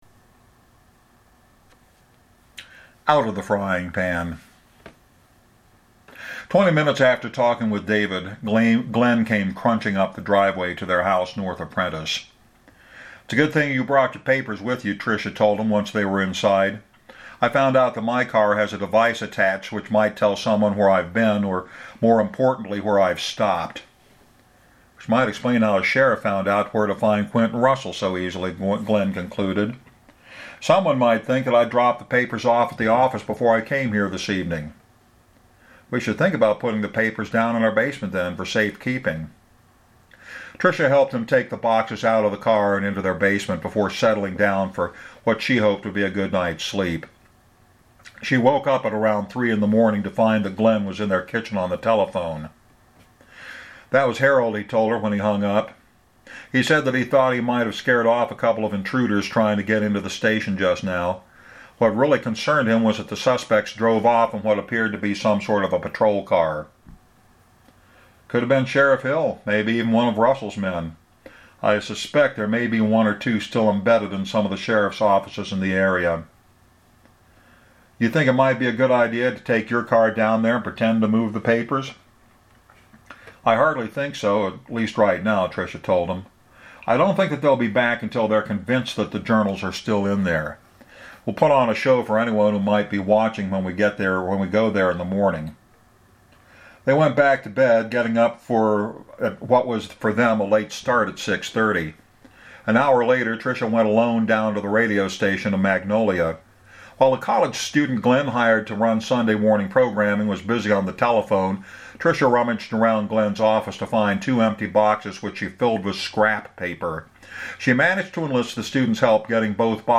After this reading, there are only 5 more readings left.